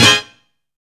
UPPER STAB.wav